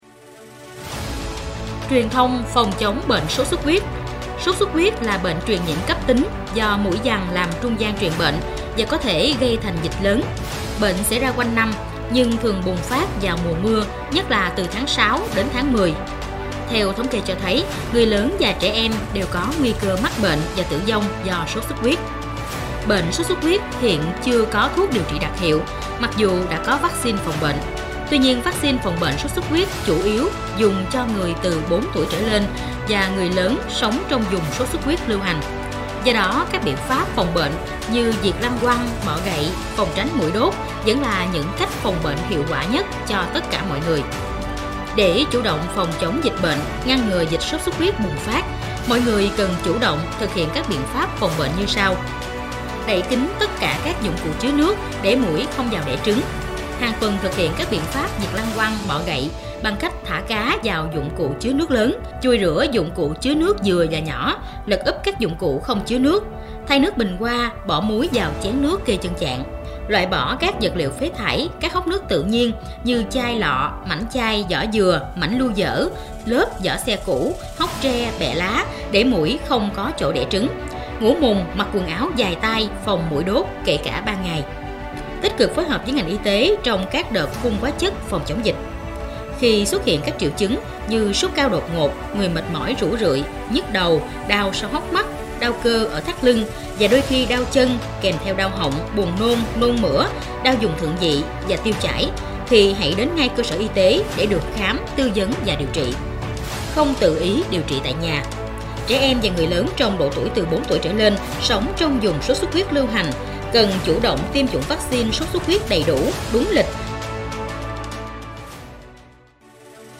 Truyền Thanh Sốt xuất huyết